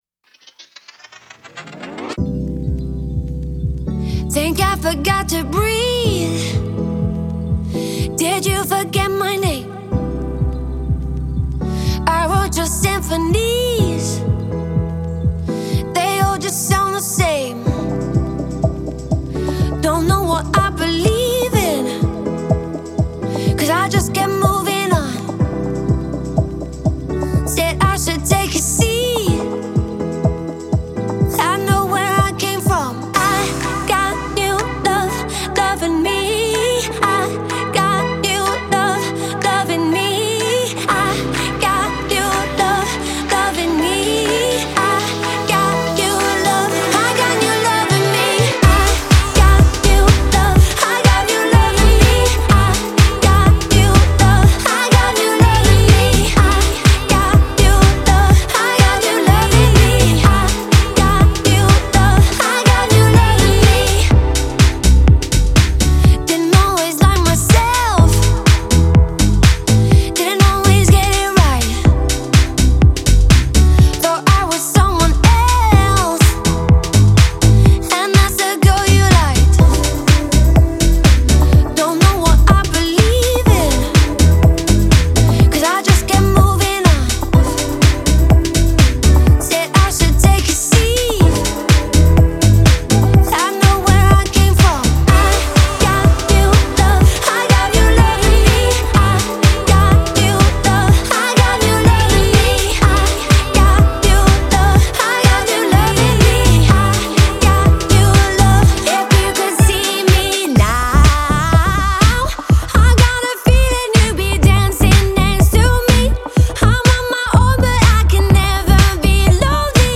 зажигательная поп-электронная композиция
мощного вокала
с динамичными битами